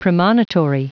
Prononciation du mot premonitory en anglais (fichier audio)